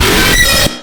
Звук скримера
Амбуш_скример.mp3.mp3